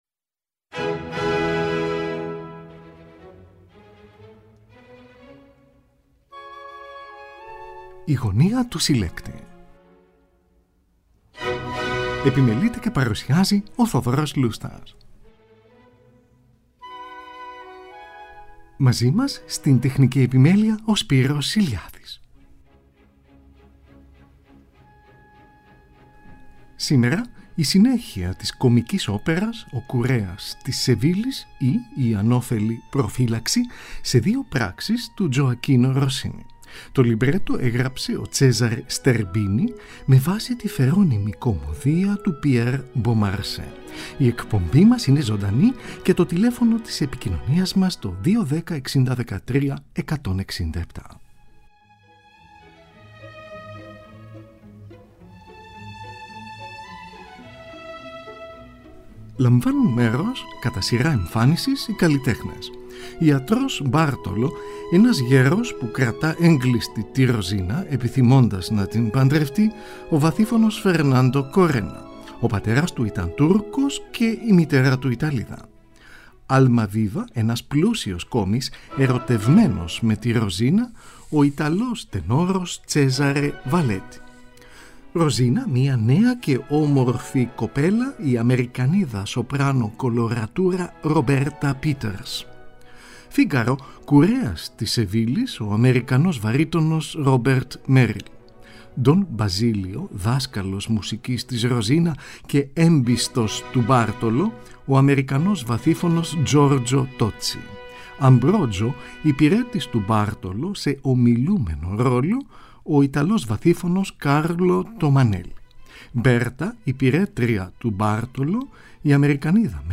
soprano coloratura
mezzo-soprano
Συμμετέχουν στρατιώτες και ένας αξιωματικός.